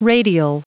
Prononciation du mot radial en anglais (fichier audio)
Prononciation du mot : radial